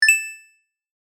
upgrade_sound.mp3